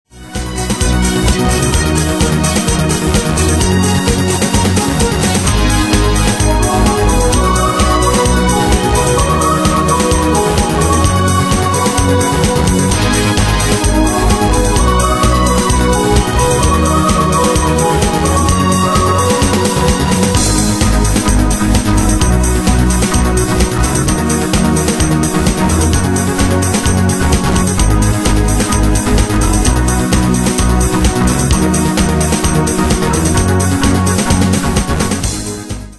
Style: Dance Année/Year: 1993 Tempo: 129 Durée/Time: 4.27
Pro Backing Tracks